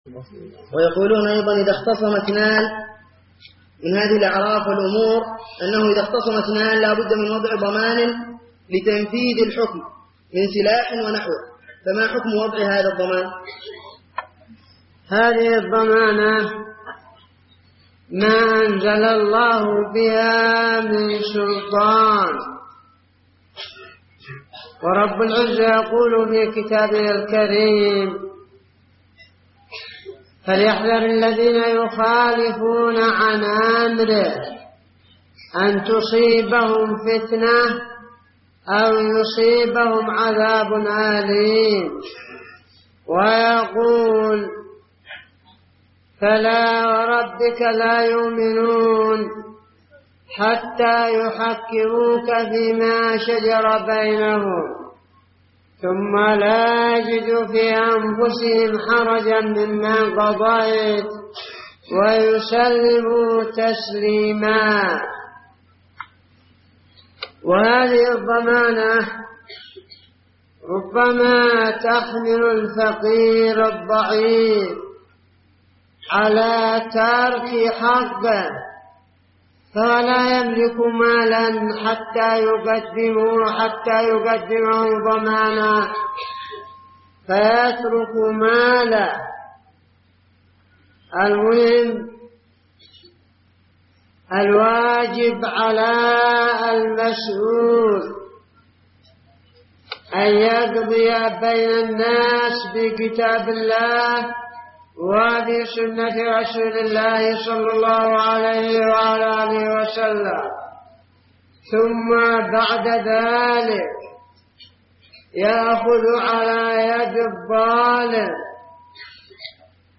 --------------- من شريط : ( أسئلة شباب الشحر بحضرموت ) .